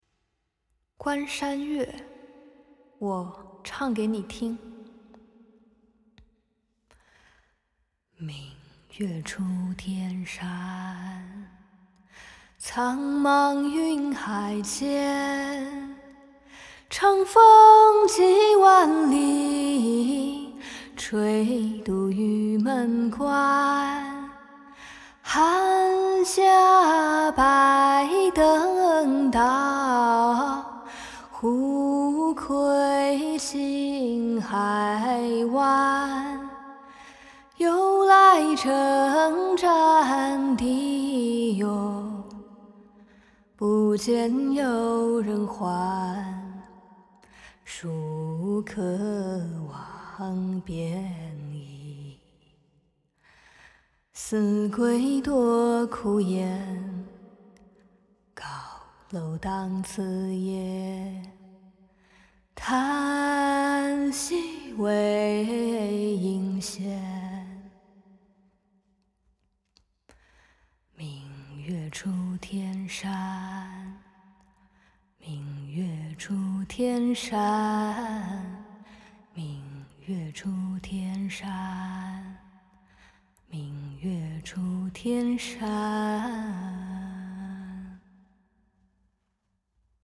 为了更好地还原此诗的意境，在这首诗的演唱上，我采用了乐府调。